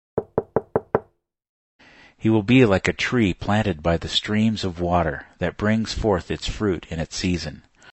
Door-knock-sound-effect_2.mp3